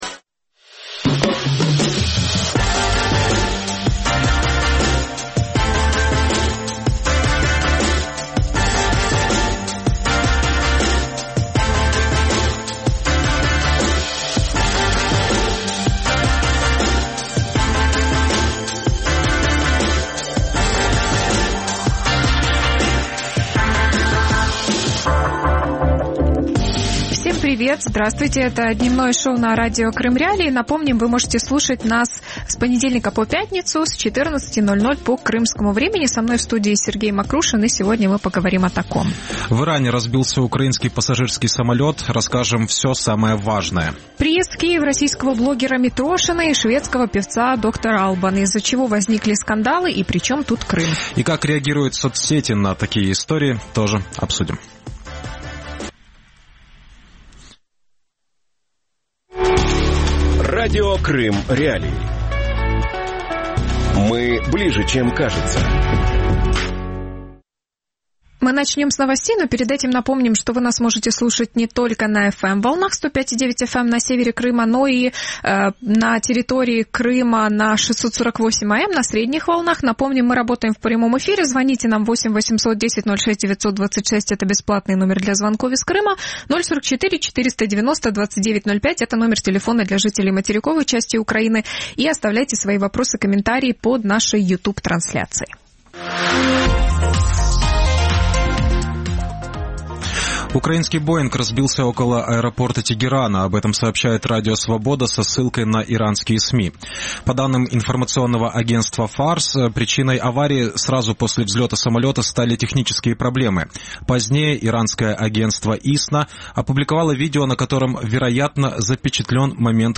Чей Крым? Пропуск на материковую Украину | Дневное ток-шоу